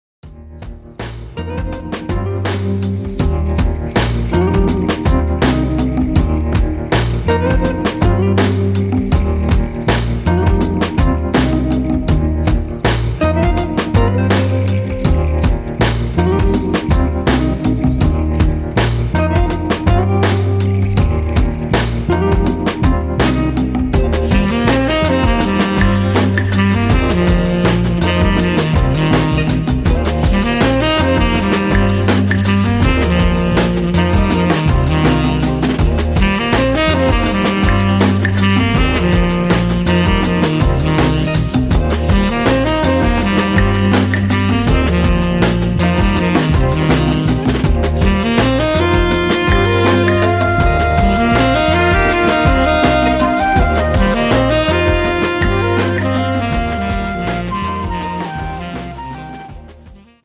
Lovely latin fusion